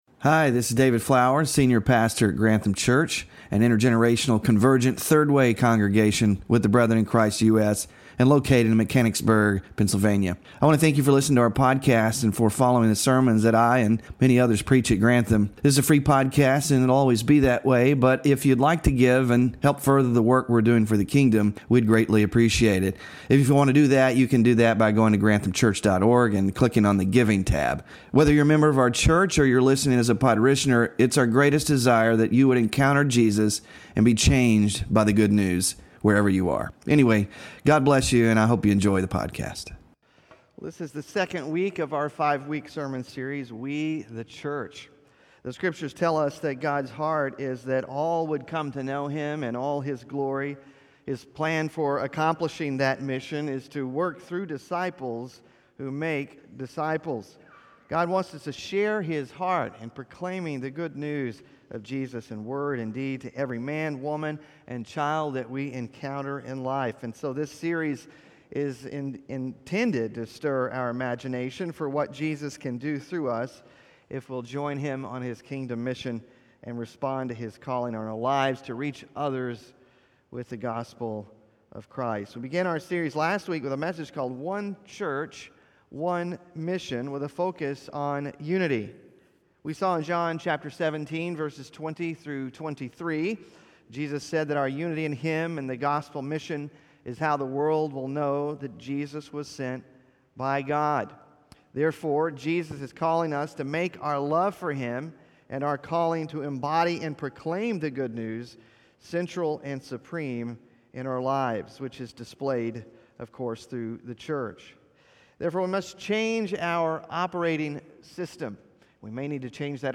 In the second message of our series